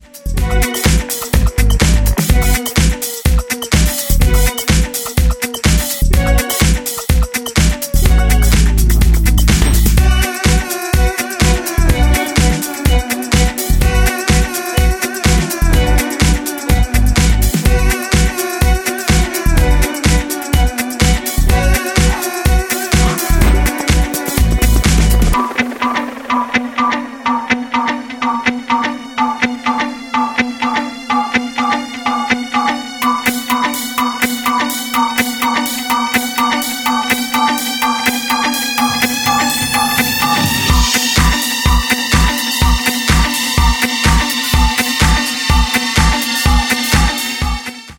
Danish DiscoRock